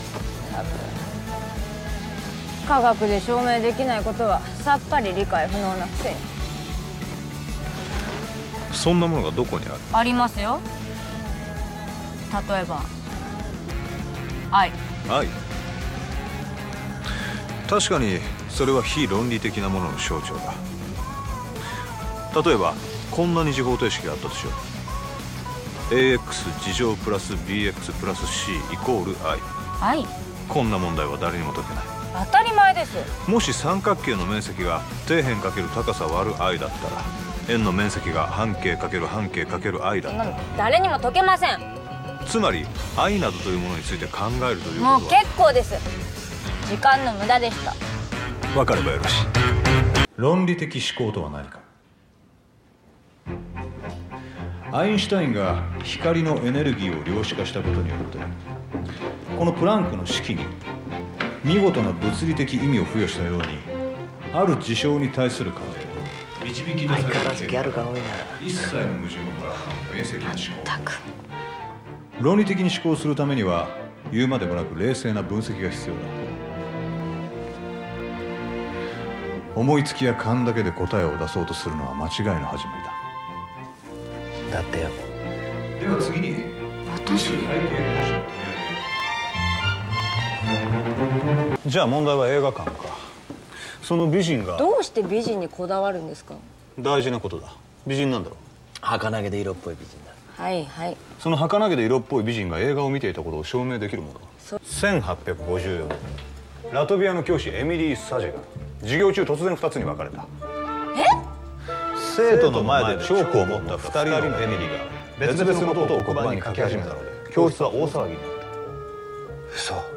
IMDb link The second scene a physics lecture.